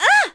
Juno-Vox_Attack3.wav